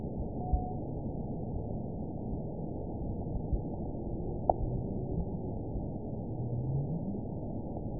event 917636 date 04/11/23 time 00:38:04 GMT (2 years, 1 month ago) score 9.55 location TSS-AB01 detected by nrw target species NRW annotations +NRW Spectrogram: Frequency (kHz) vs. Time (s) audio not available .wav